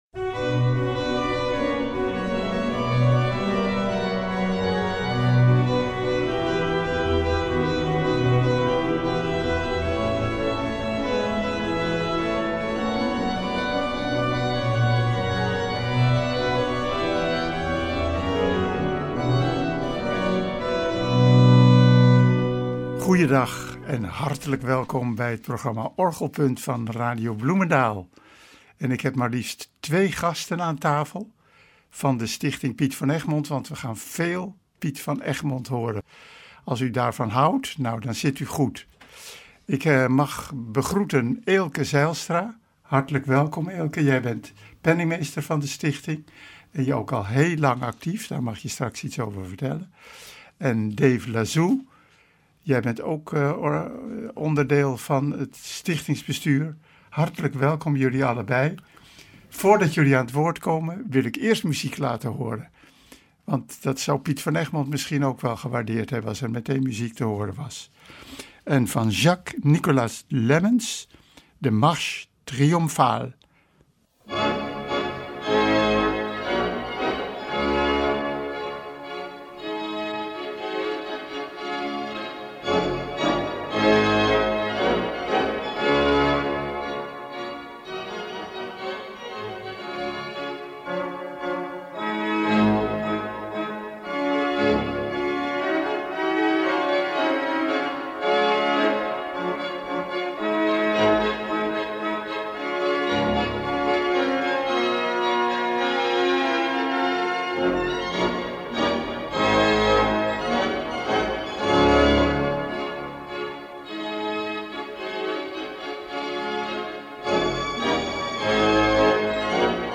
Aansprekende improvisaties over bekende liederen, afgewisseld met klassieke stukken van Bach tot Hendrik de Vries passeren de revue.